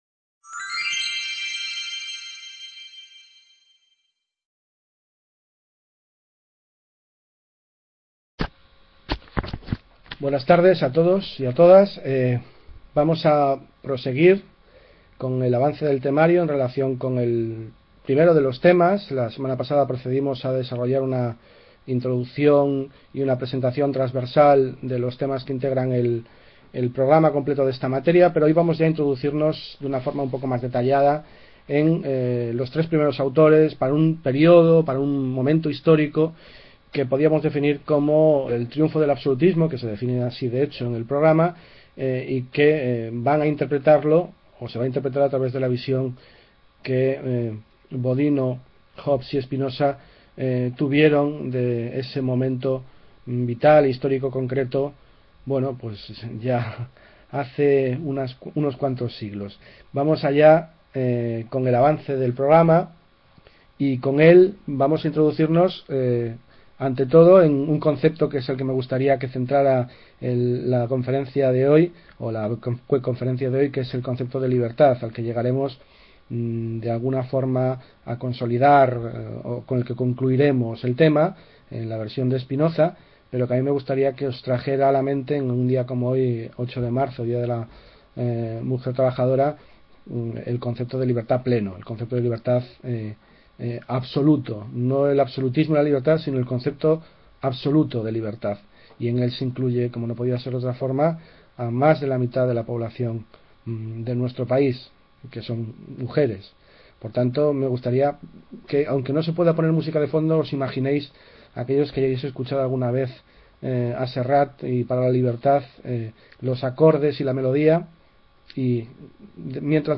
Webconferencia (8-III-2013): ABSOLUTISMO Y ¿LIBERTAD?,…